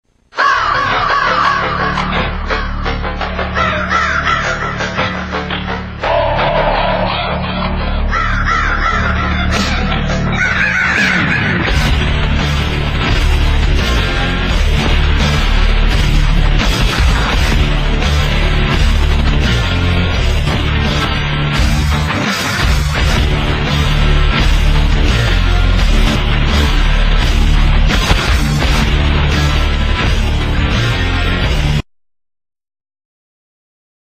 themes